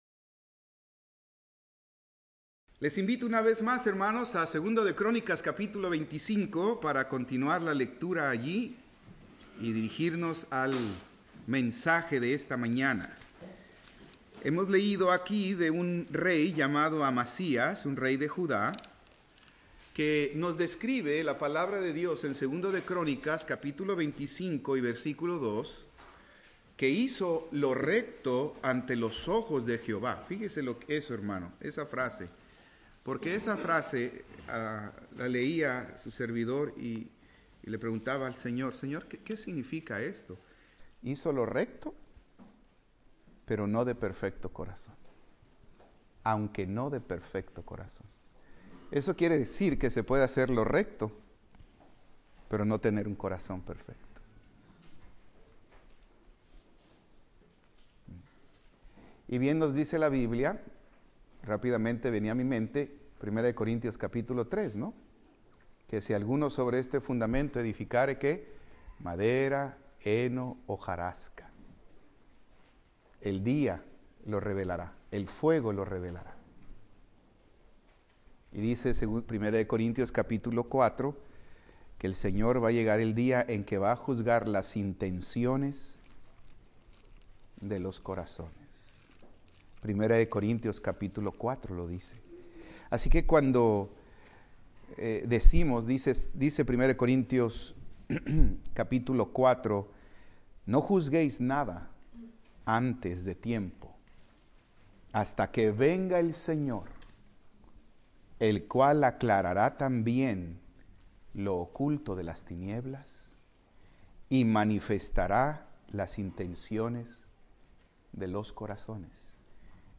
Servicio matutino